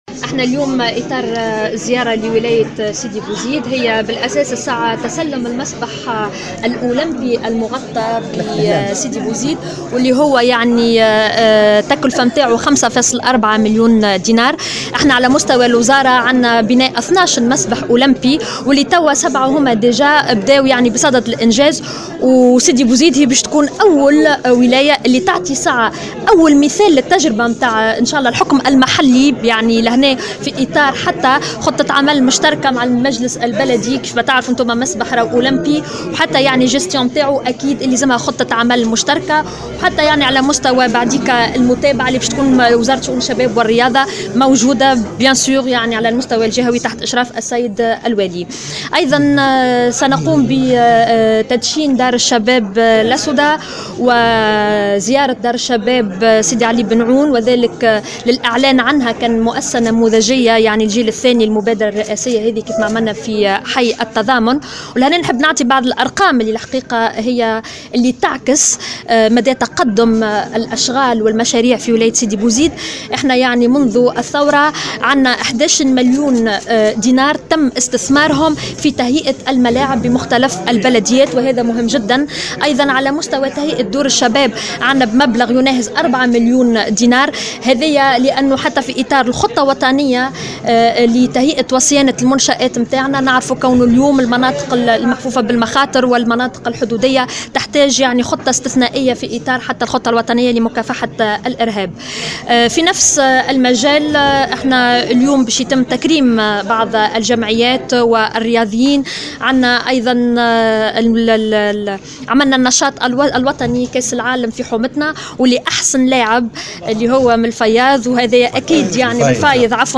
و صرّحت الوزيرة لمراسل الجوهرة 'اف ام' أنّ هذه الزيارة جاءت في إطار إستلام المسبح الأولمبي المغطى بسيدي بوزيد و الذي تبلغ تكلفته 5.4 مليون دينار و هو أول مسبح أولمبي بالجمهورية التونسية.